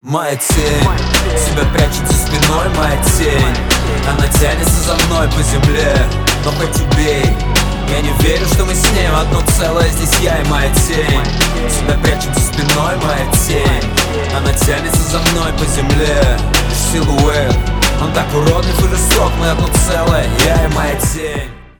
• Качество: 320, Stereo
мужской голос
русский рэп
спокойные